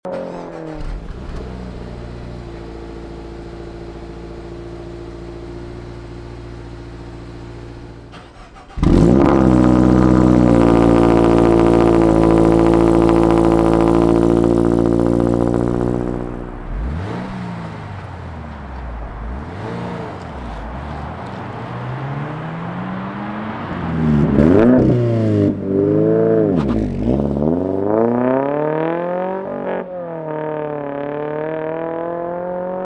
AWE Touring Edition Catback Exhaust for the Toyota GR86 / Subaru BRZ